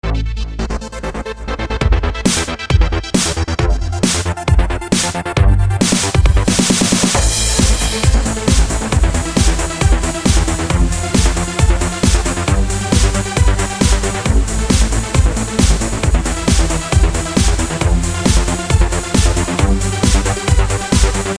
硬式舞蹈的例子
Tag: 135 bpm Dance Loops Synth Loops 3.59 MB wav Key : Unknown